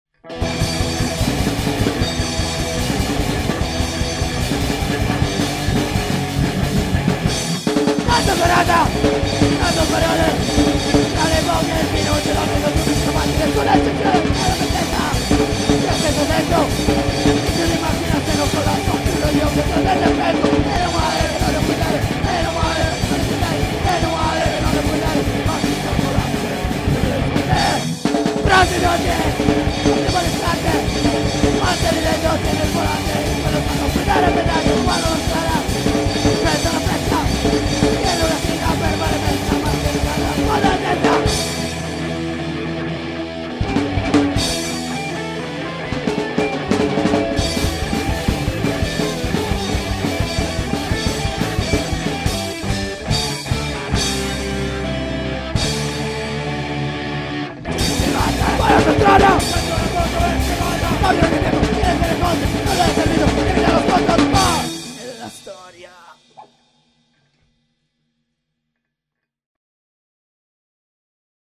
Genere: Punk Hardcore
chitarra e voce
chitarra e cori